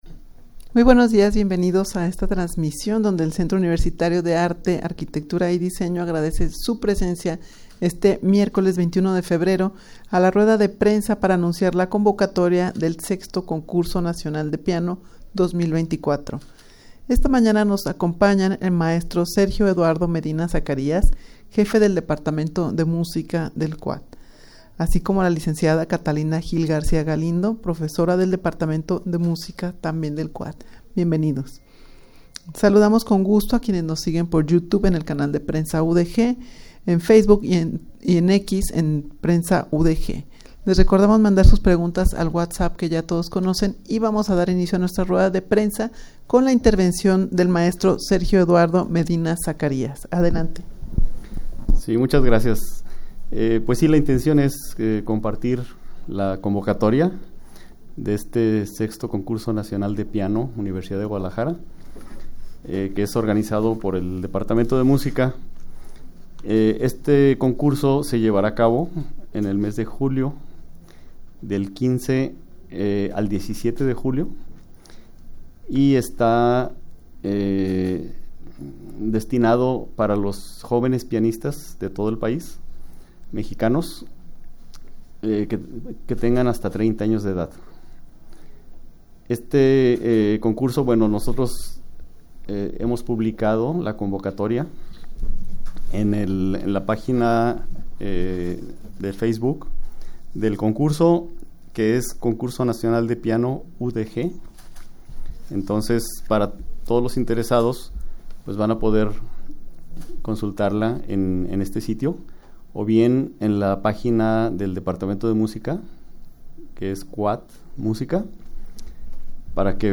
Audio de la Rueda de Prensa
rueda-de-prensa-para-anunciar-la-convocatoria-del-vi-concurso-nacional-de-piano-2024.mp3